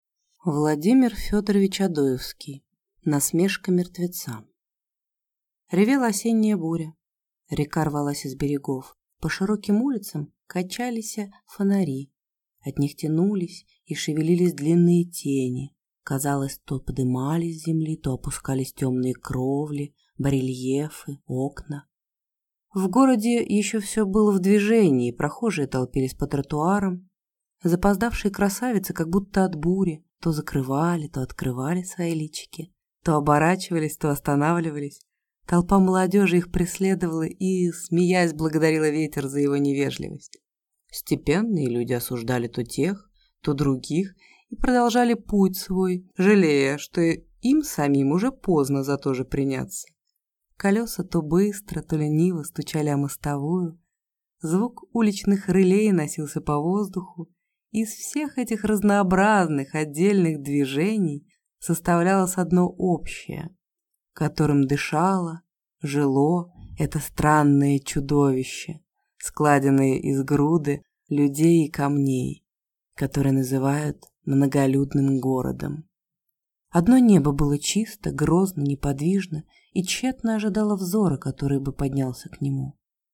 Аудиокнига Насмешка мертвеца | Библиотека аудиокниг